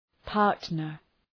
Προφορά
{‘pɑ:rtnər}